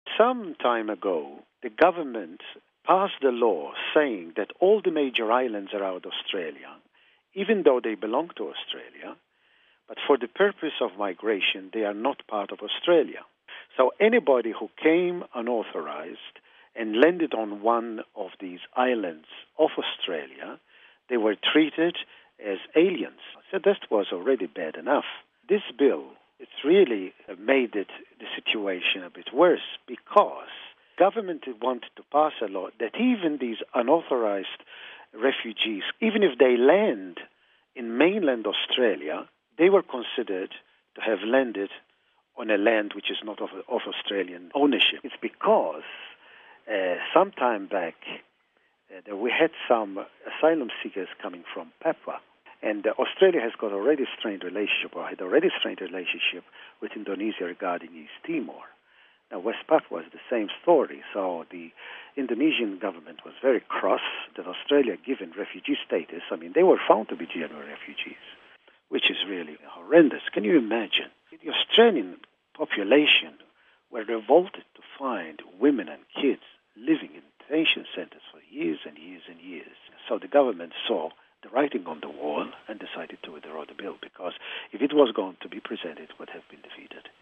(14 August 2006 - RV) Australian Prime Minister John Howard announced the withdrawal of a controversial immigration and asylum bill. Bishop Joseph Grech, Delegate on Immigration and Refugee Matters for the Australian Bishops' Conference, told us why the governments' withdrawal of its "Off-Shore Processing Bill" was a victory for the fair and humane treatment of asylum seekers coming to the country.